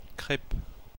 A crêpe or crepe (/krp/ KRAYP[3] or /krɛp/ KREP, French: [kʁɛp]
Fr-Paris--crêpe.ogg.mp3